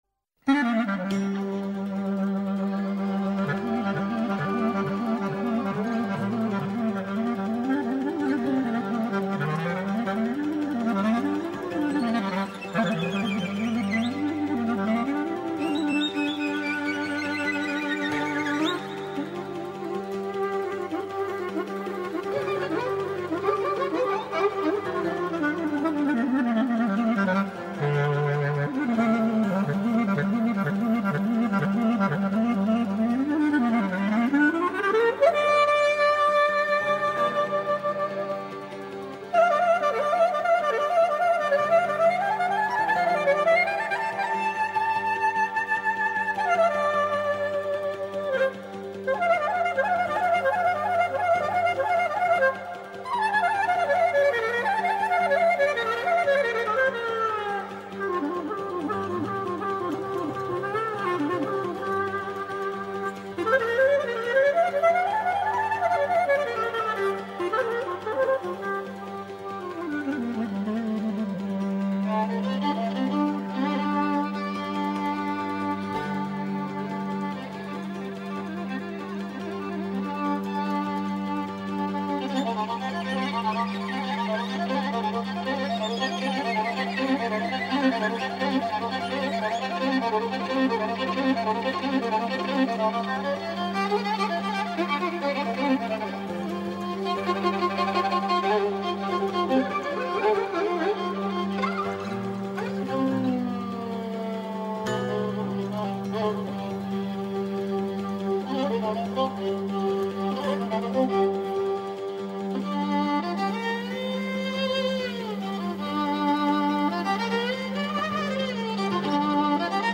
Ένα καθημερινό μουσικό ταξίδι στην παράδοση της Ελλάδας. Παλιές ηχογραφήσεις από το αρχείο της Ελληνικής Ραδιοφωνίας, νέες κυκλοφορίες δίσκων καθώς και νέες ηχογραφήσεις από τα Μουσικά Σύνολα της ΕΡΤ.